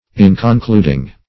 Inconcluding \In`con*clud"ing\, a.
inconcluding.mp3